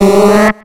Cri de Queulorior dans Pokémon X et Y.